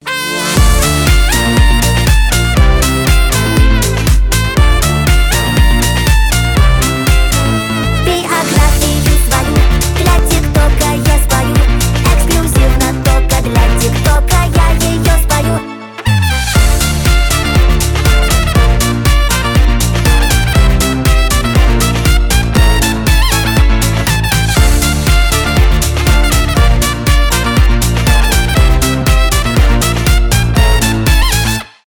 поп
саксофон , танцевальные